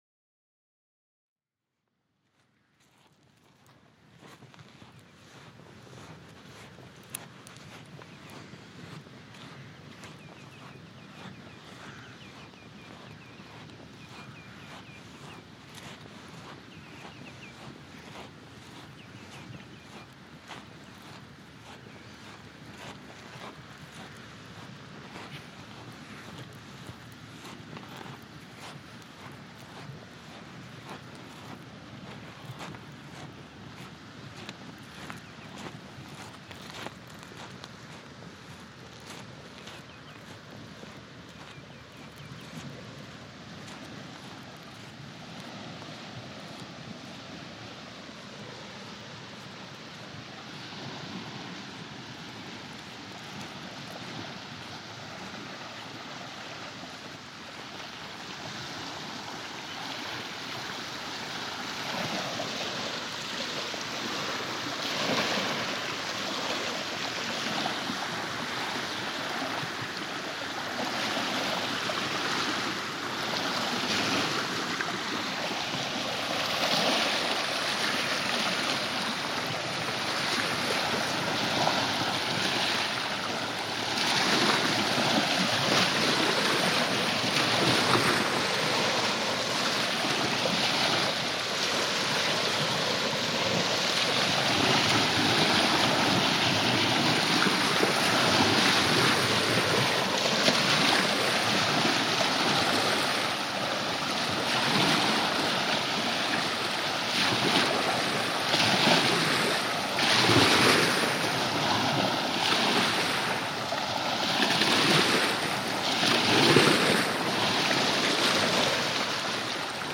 Beschreibung vor 4 Monaten Am Nieblumer Strand unterhalb des Bohlenwegs kann man kilometerweit den Außengrenzen der Insel folgen. Bei Sonnenaufgang auch nahezu alleine.
Means of transport: On foot Shoes: Barefoot Distance covered: approx. 1500 meters Weather: Light wind, rising sun Use headphones to benefit from the binaural effect.